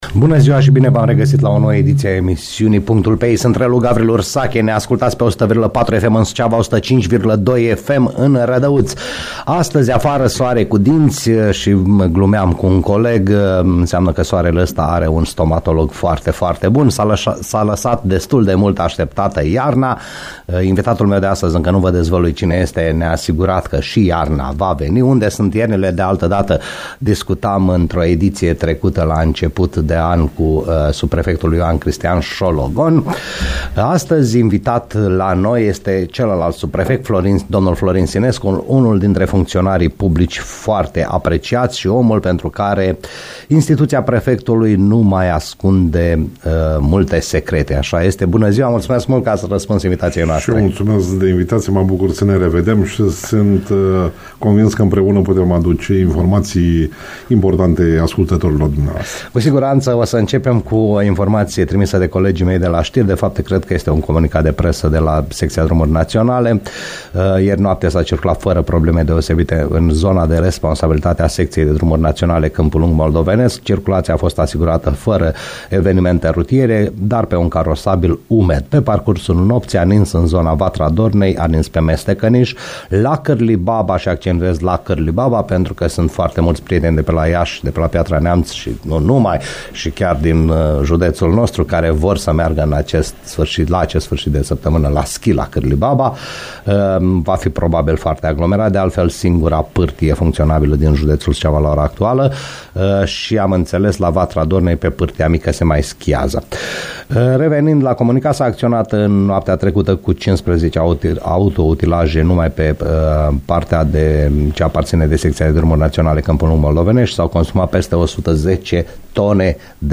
a fost, astăzi, la PUNCTUL PE I subprefectul județului Suceava, Florin Sinescu.